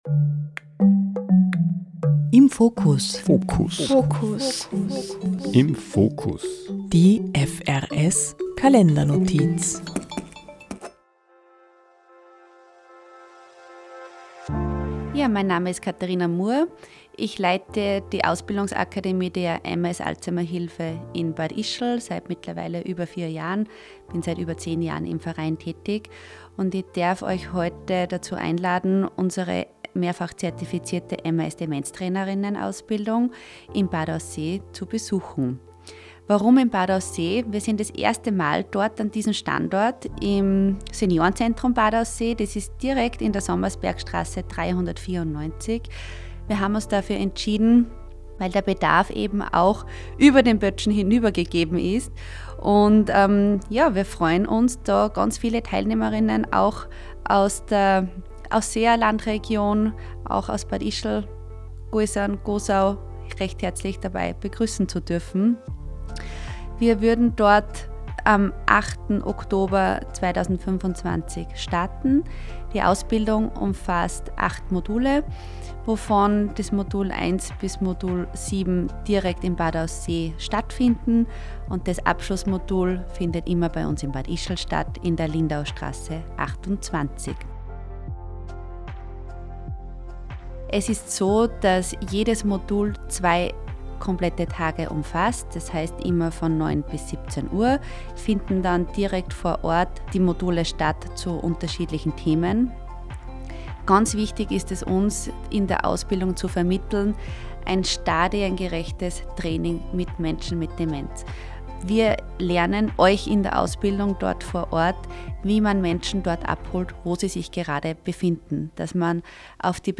Aber hören Sie selbst: Radio Interview Hier anhören